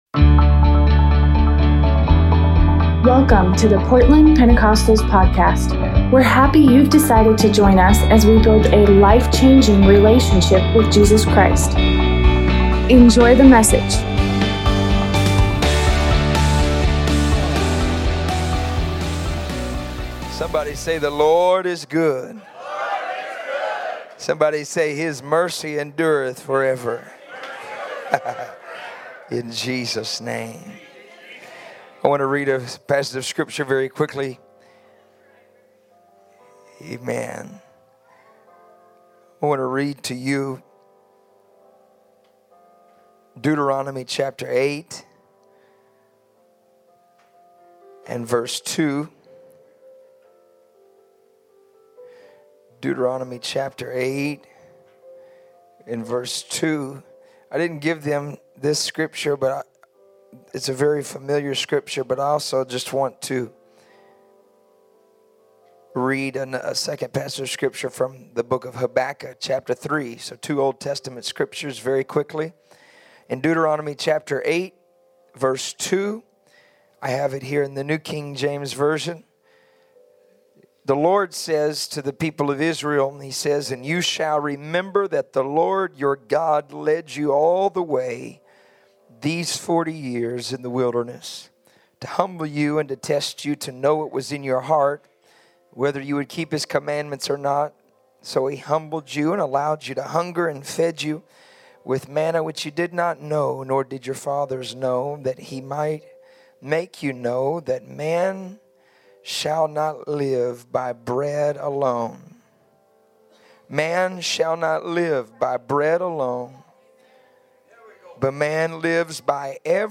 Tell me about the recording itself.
Revival service